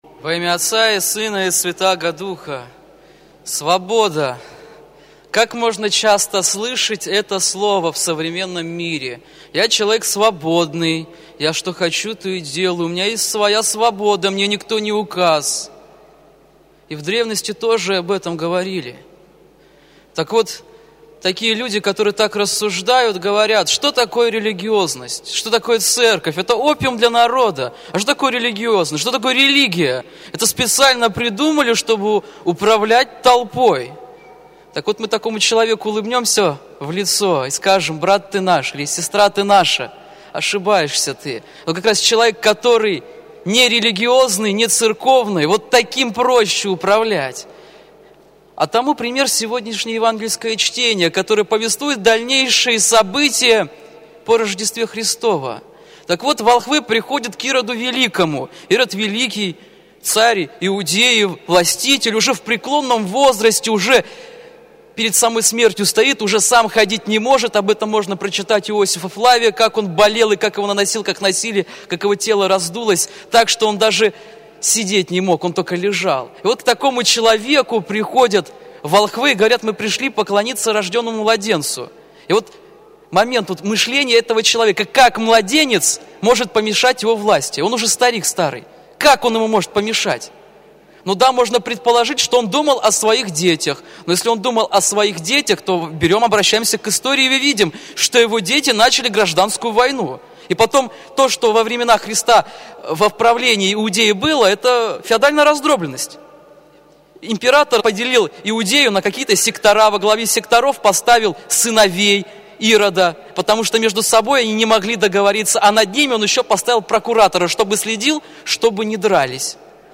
Проповедь на Литургии